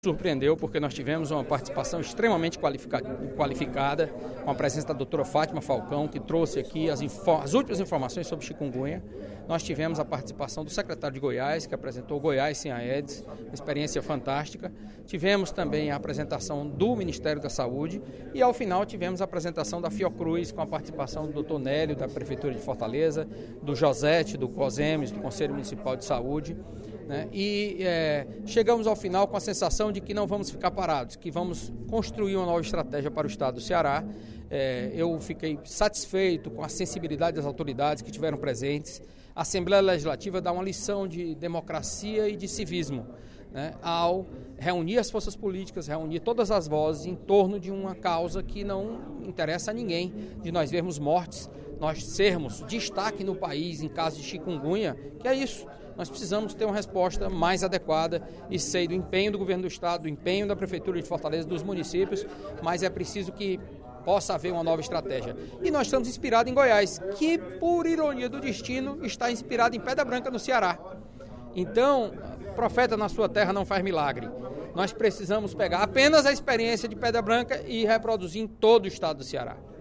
O deputado Carlos Matos (PSDB) destacou, no primeiro expediente da sessão plenária desta terça-feira (28/03), o seminário “Chikungunya, conhecer para prevenir e combater – Experiências e atitudes no enfrentamento do Aedes aegypti”, realizado na última segunda-feira (27/03) pela Frente Parlamentar de Combate ao Aedes Aegypti da Assembleia Legislativa do Ceará.